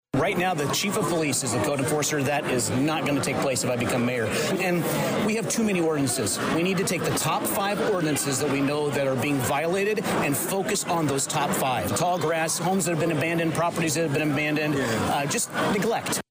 Full Crowd Comes to St. Mary’s Church for Westville Candidates Forum